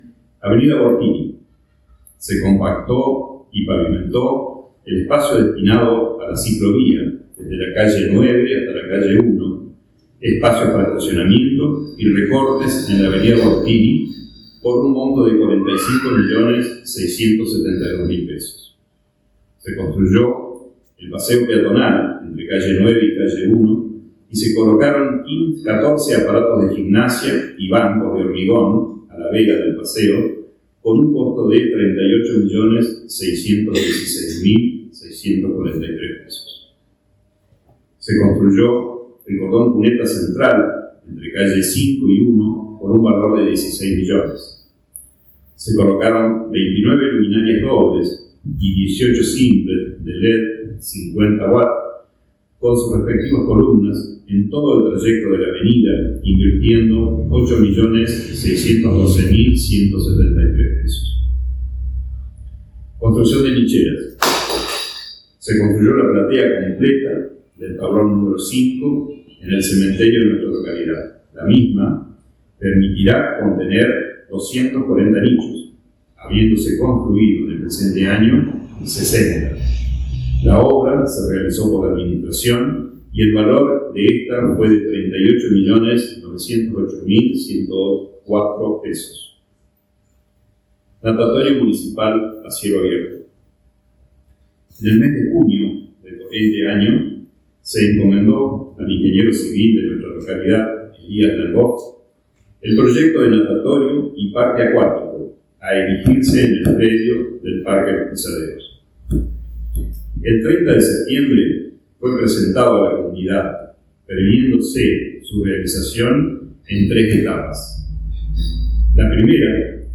Discurso de apertura sesiones 2026 Concejo Deliberante de Victorica.
Discurso del intendente Hugo Kenny